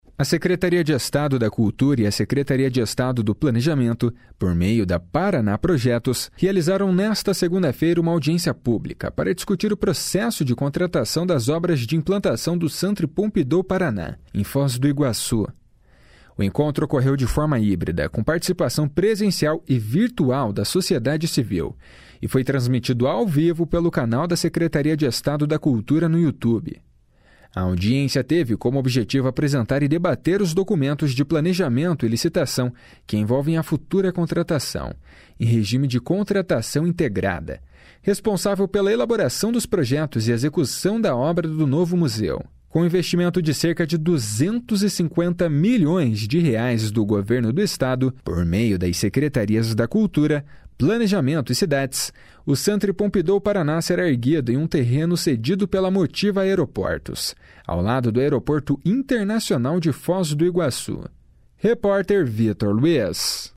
audiencia_publica_debate.mp3